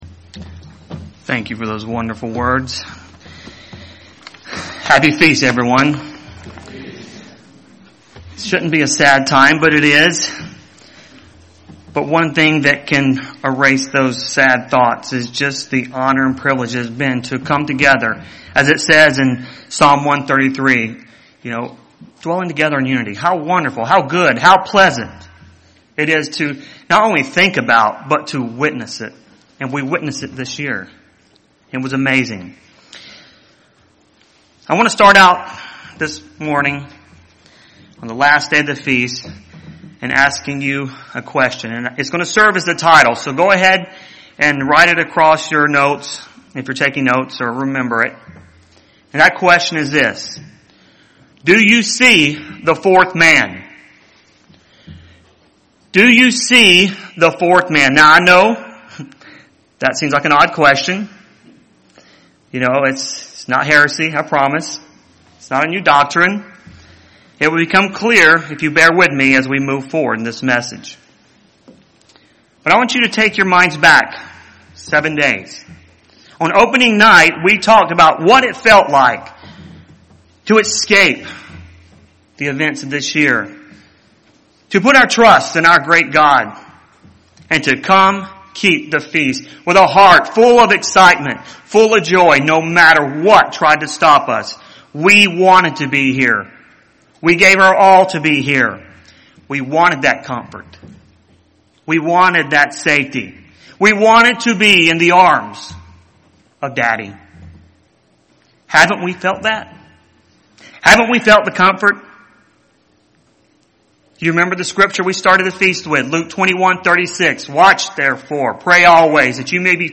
This sermon was given at the Cincinnati, Ohio 2020 Feast site.